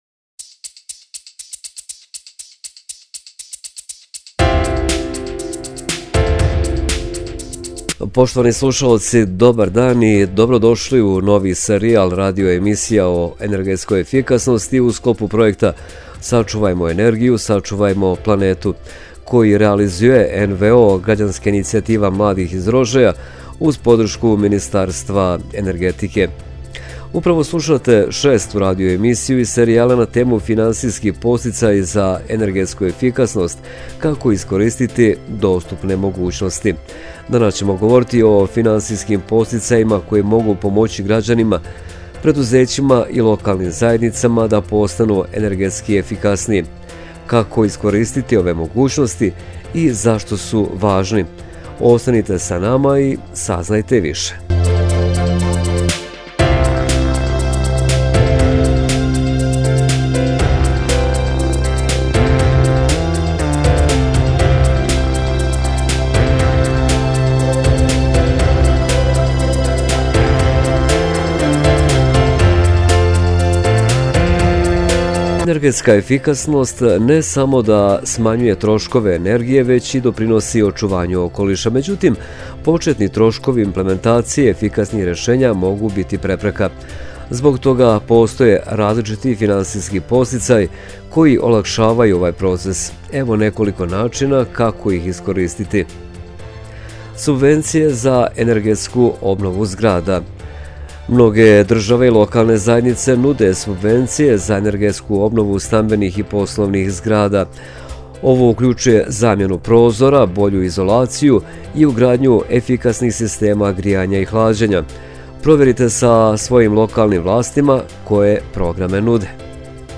Šesta radio emisija “Sačuvajmo energiju – sačuvajmo Planetu”